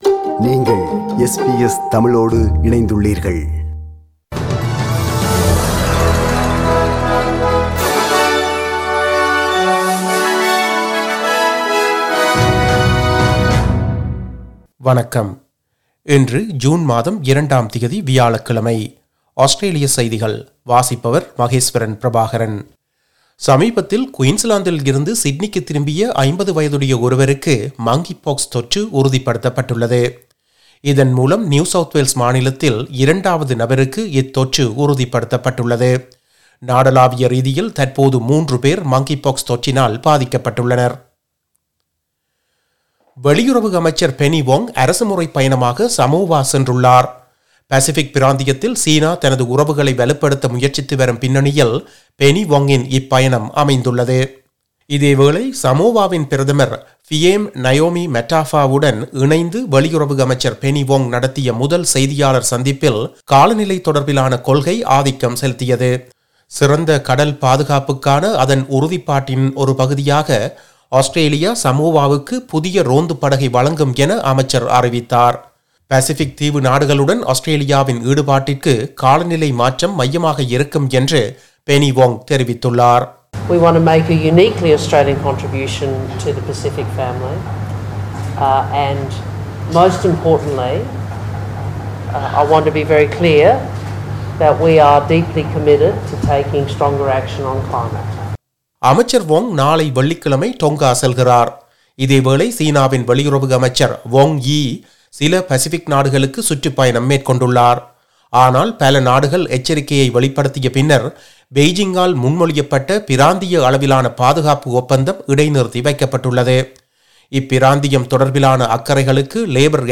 Australian news bulletin for Thursday 02 June 2022.